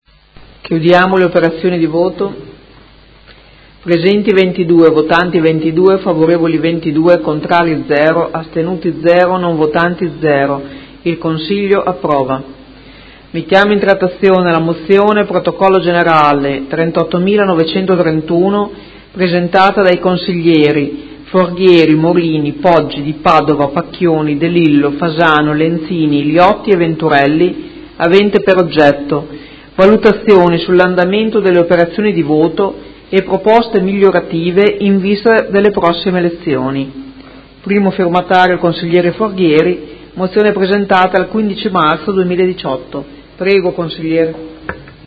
Presidentessa — Sito Audio Consiglio Comunale
Seduta del 26/04/2018 Mette ai voti. Ordine del Giorno presentato dal Consigliere Pellacani (Energie per l’Italia) avente per oggetto: Camera e Senato approvino, mediante intervento regolamentare, un’equiparazione dell’età pensionabile dei parlamentari a quella prevista nel sistema generale.